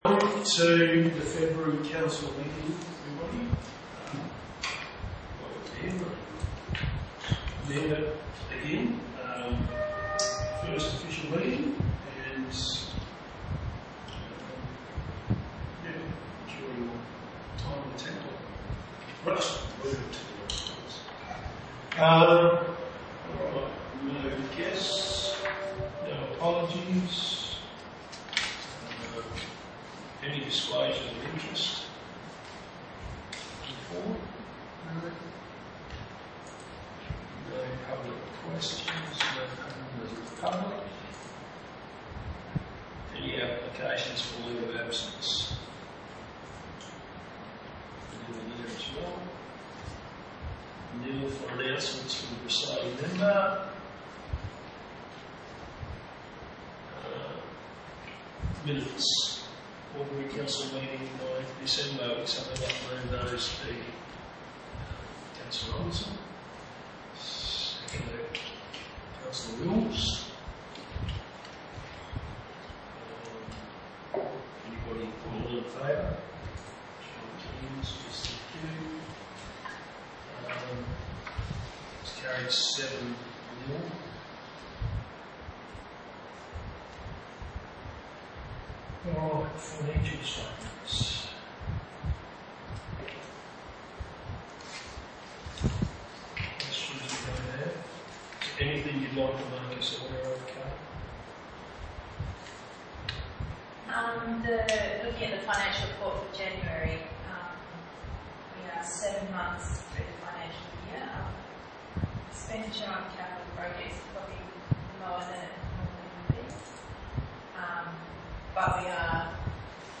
20 February 2025 Ordinary Meeting of Council
Location: Tambellup Council Chambers